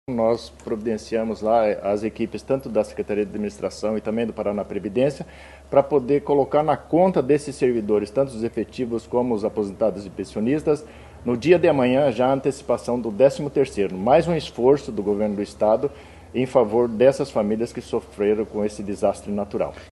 Sonora do secretário da Administração e da Previdência, Luizão Goulart, sobre a antecipação do 13º salário de servidores de cidades atingidas por tornado